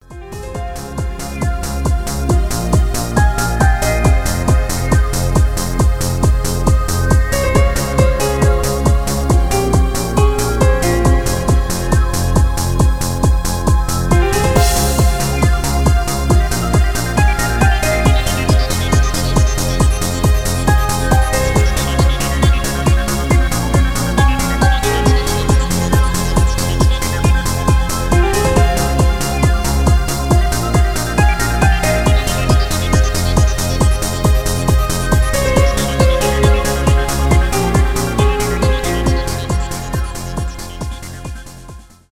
евродэнс , мелодичные , транс , без слов